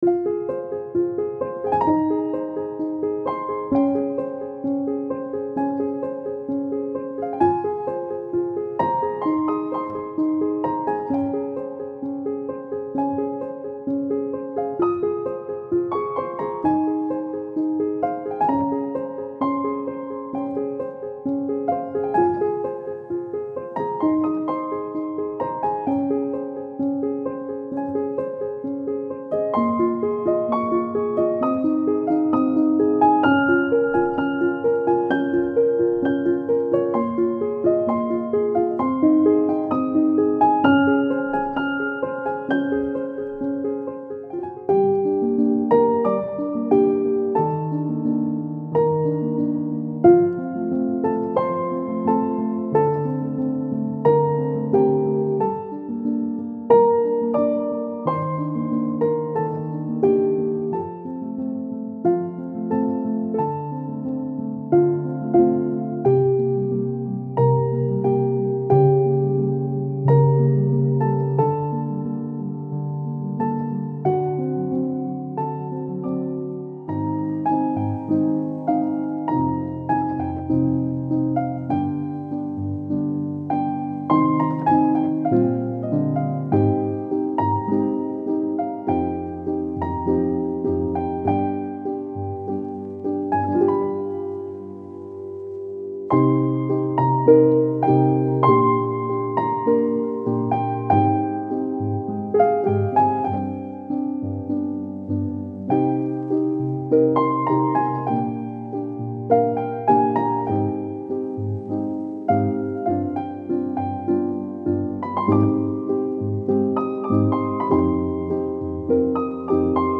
Genre:Filmscore
さらに、トラックに感情豊かな鍵盤を加えたいと考えるプロデューサーにとっても必携の内容となっています。
デモサウンドはコチラ↓
60-143 BPM
60 Wav Loops (20 Mixdowns, 40 Piano Layers)